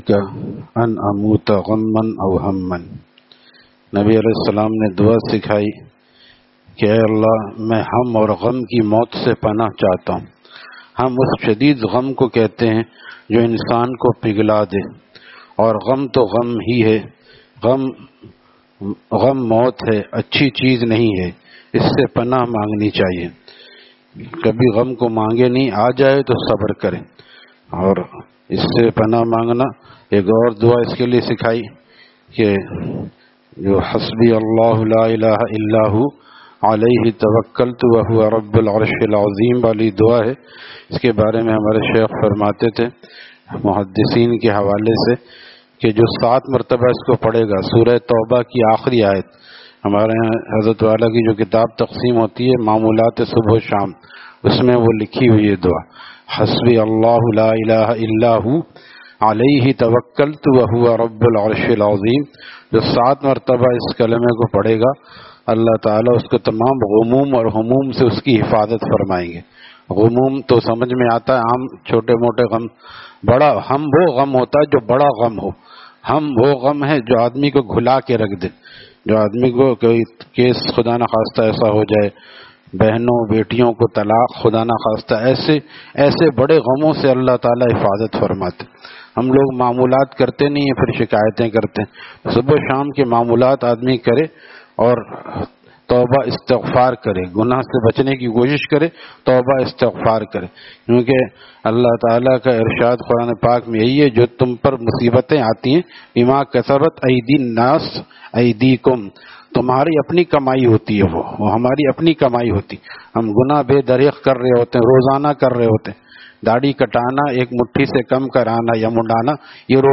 Taleem After Fajor at Jama Masjid Gulzar e Muhammadi, Khanqah Gulzar e Akhter, Sec 4D, Surjani Town